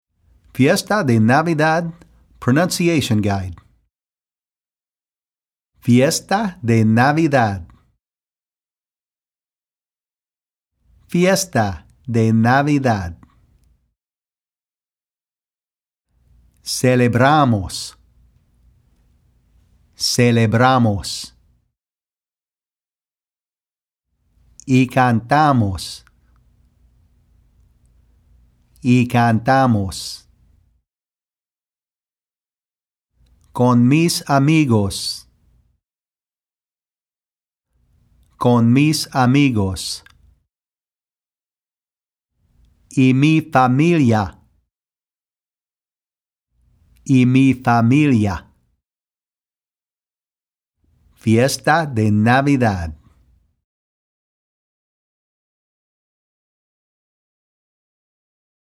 Fiesta De Navidad - Pronunciation Guide
This is a pronunciation guide of the Spanish words used in the song.
x282FiestaDeNavidadPronunciationGuide.mp3